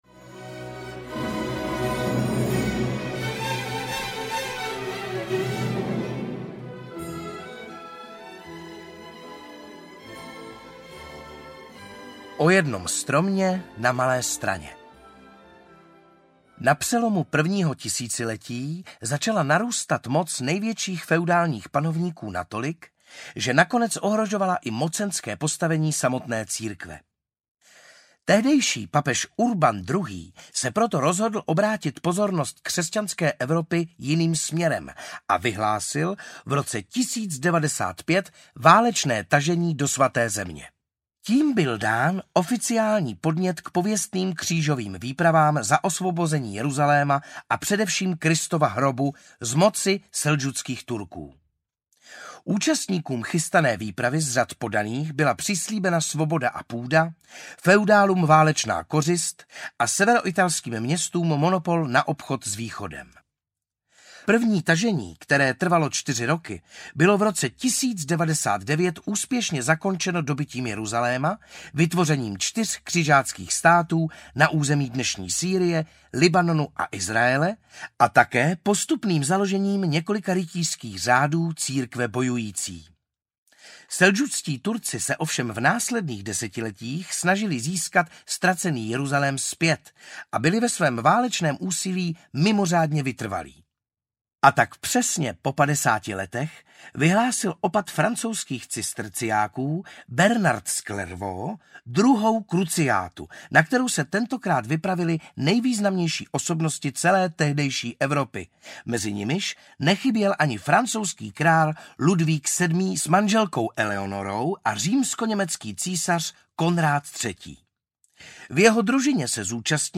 Kniha o staré Praze audiokniha
Ukázka z knihy